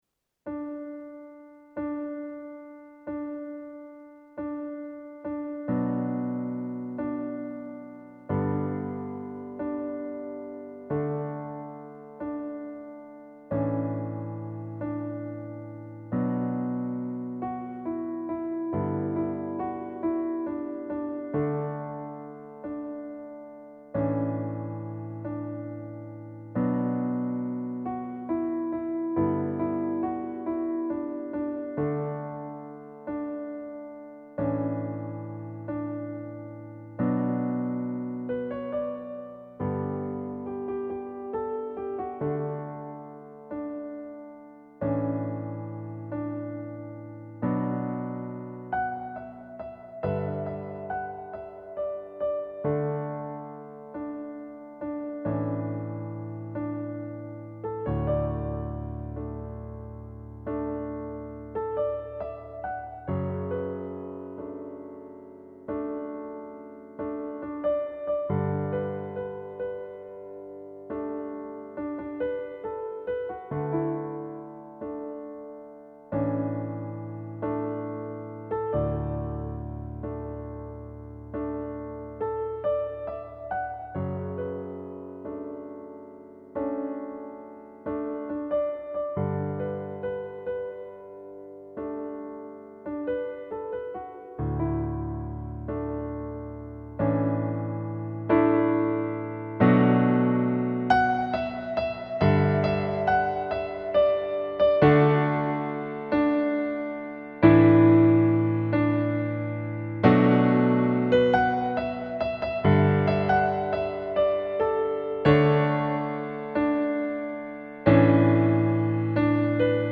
Say-Something-Backing.mp3